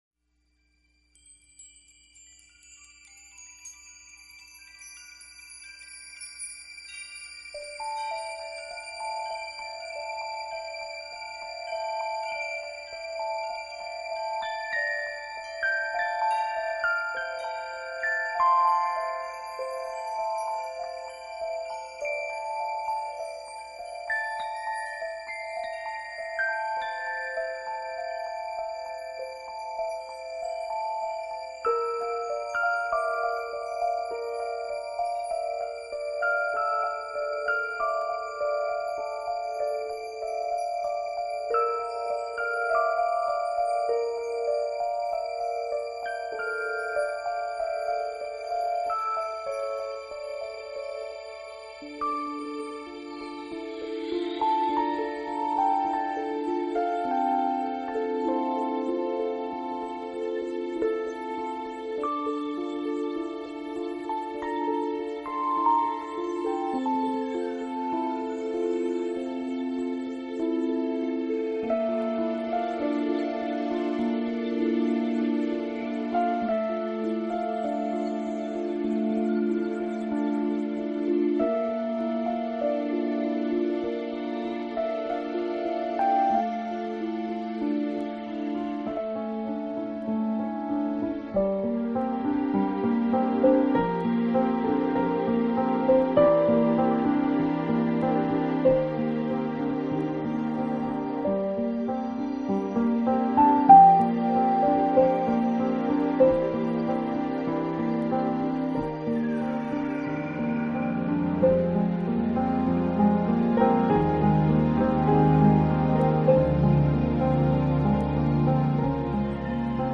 【New Age钢琴】